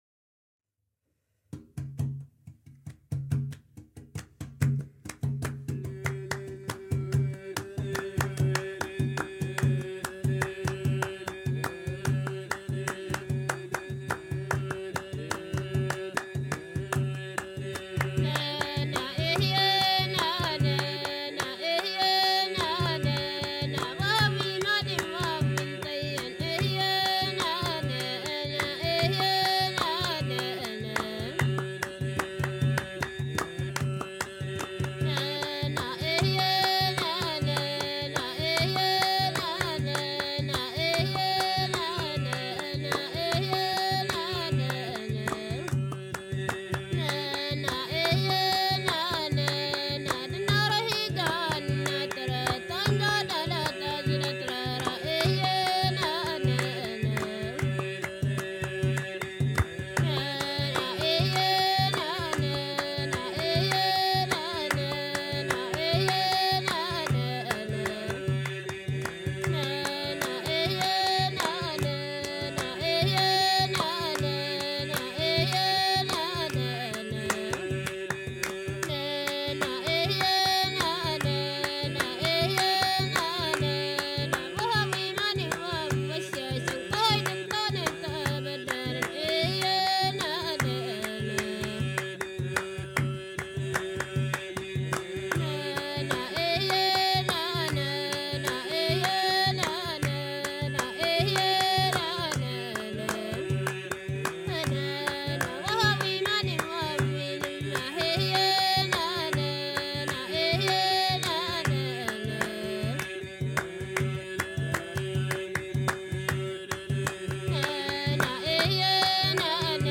Tuareg guitar band